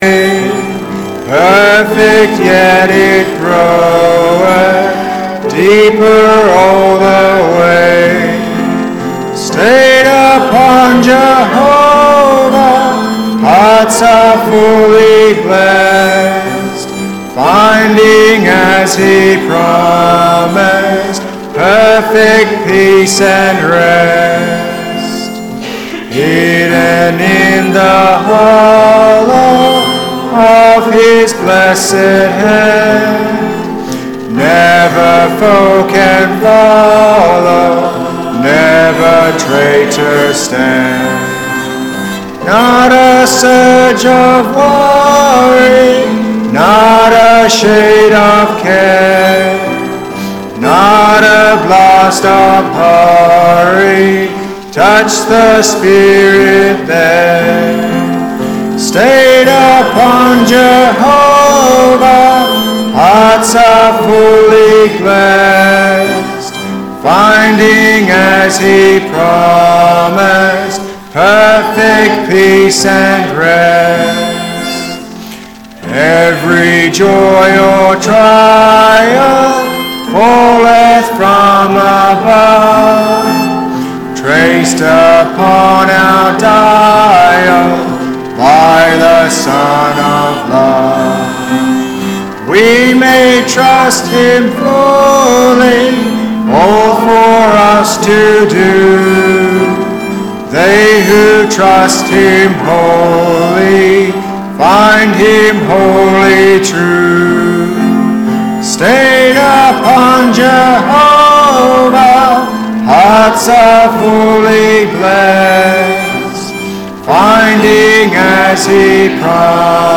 Passage: Hebrews 4:14-16, 13:5-6 Service Type: Sunday AM The final version of this service